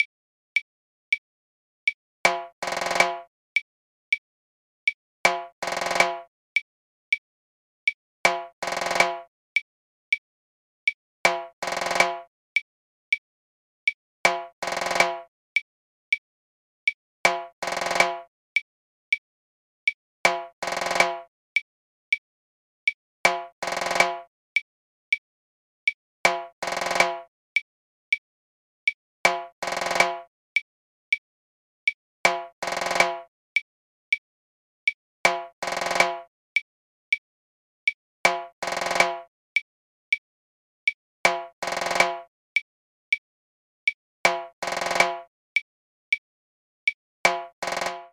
Il est composé d'un coup accentué suivi d'un roulement de 7 ou 9 coups en fonction de la vitesse de la musique :
Lorsque la clave est présente et que le rythme est joué sur le tresillo de la clave, l'accent est avancé d'un demi-temps et tombe sur la bombo note.
Abanico sur une clave 3/2
danzon_timbales_abanico2.mp3